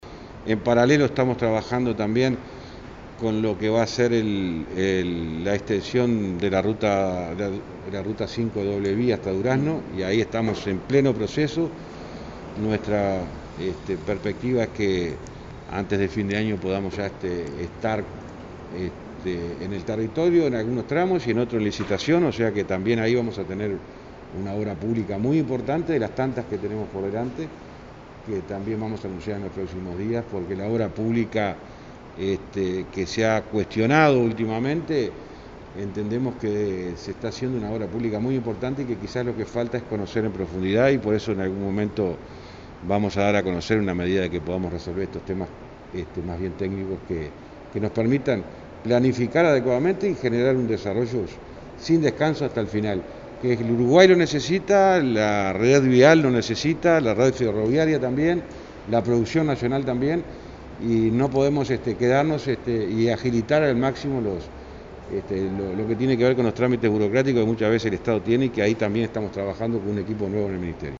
Escuche a José Luis Falero aquí